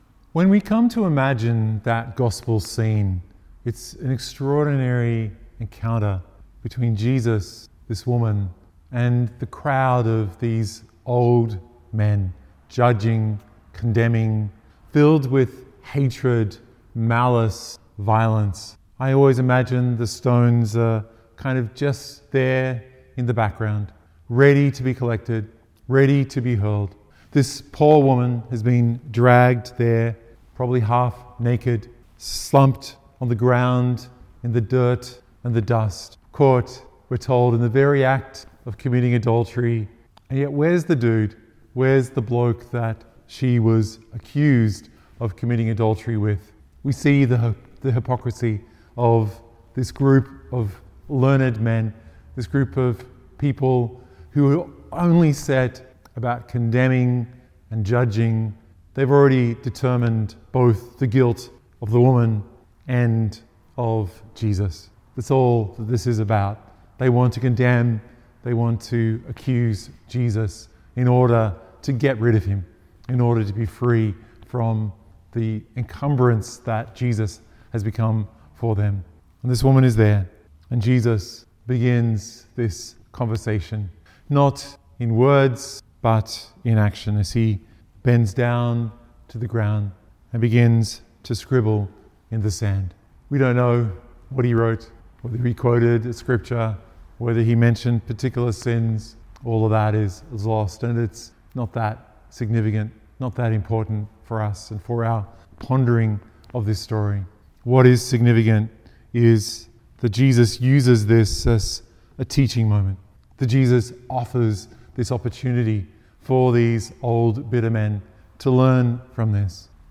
Lent, Sunday 5, Year C
This reflection is based on a homily